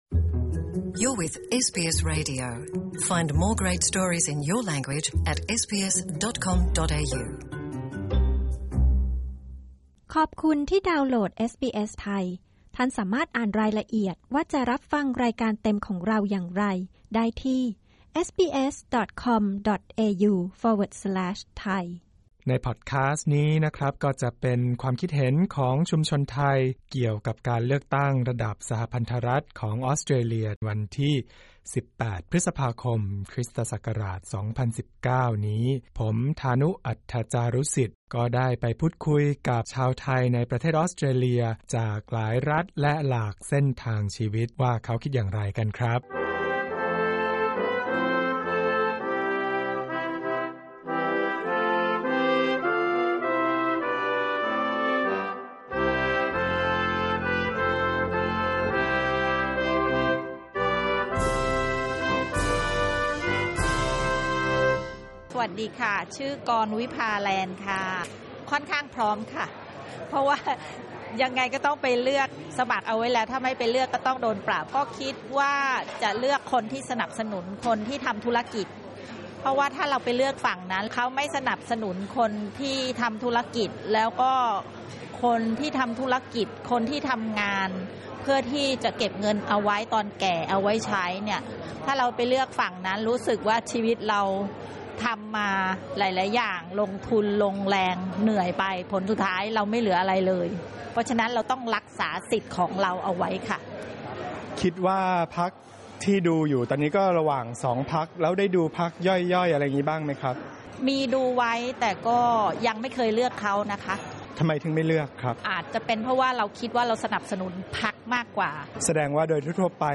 กดปุ่ม (▶) ด้านบนเพื่อฟังสัมภาษณ์เปิดใจชาวไทยอย่างเป็นกันเอง